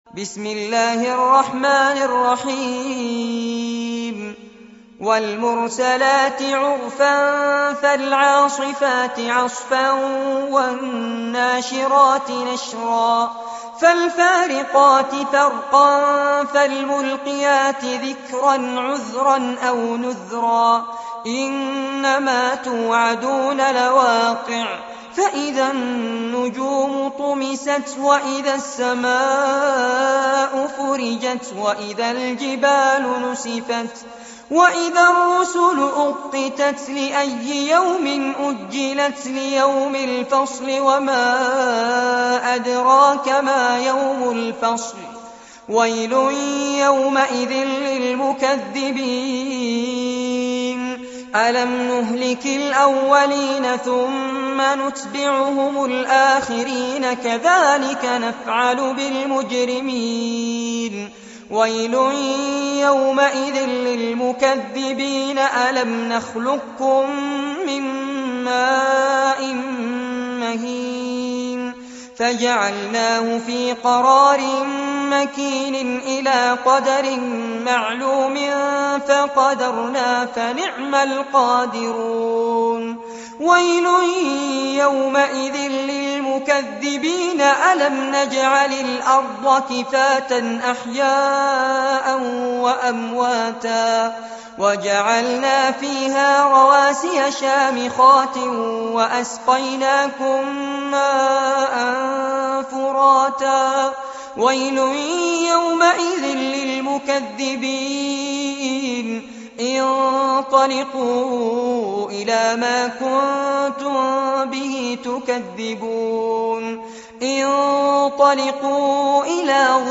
عنوان المادة سورة المرسلات- المصحف المرتل كاملاً لفضيلة الشيخ فارس عباد جودة عالية